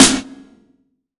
SNARE 068.wav